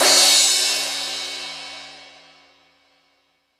Metal Drums(42).wav